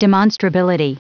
Prononciation audio / Fichier audio de DEMONSTRABILITY en anglais
Prononciation du mot : demonstrability